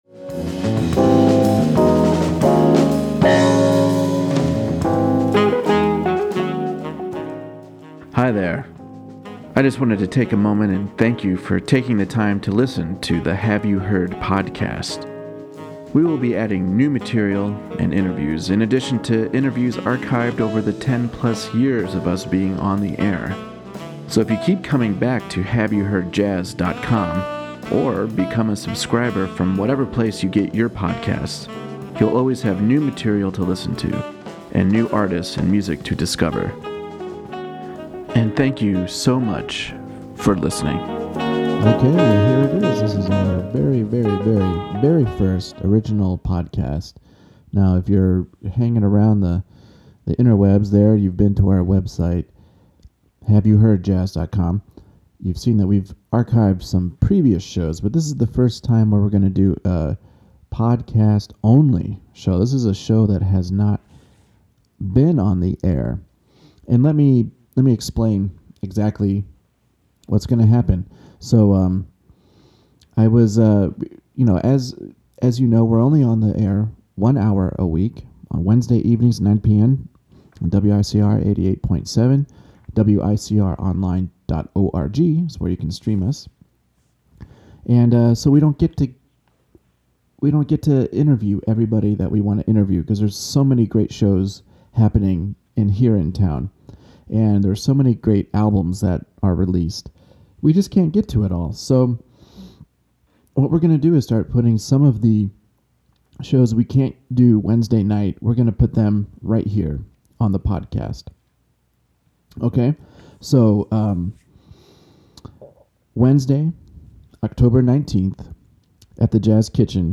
tenor sax
trumpet
drums
piano
bass
alto sax